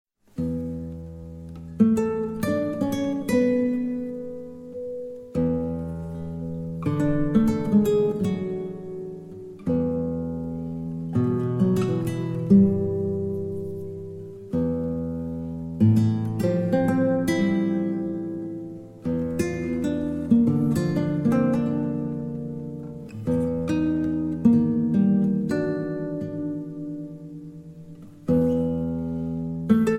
Shimmering Guitar and Lute Duets Composed and Improvised
With its strong modal structure